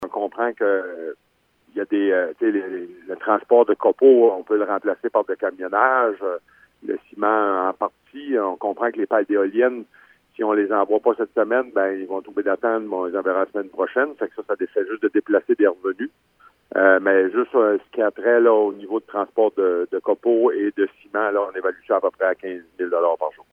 La situation est différente en ce qui a trait au convoi de pales d’éoliennes qui doit partir la semaine prochaine, note le président de l’entité gestionnaire du rail, le maire Éric Dubé :